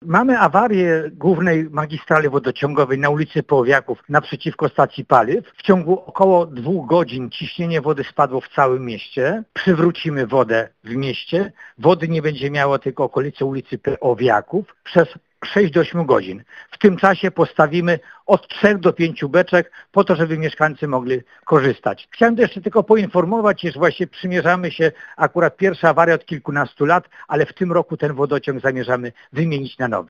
– Mamy awarię głównej magistrali wodociągowej na ulicy Peowiaków – mówi w rozmowie z Radiem Lublin.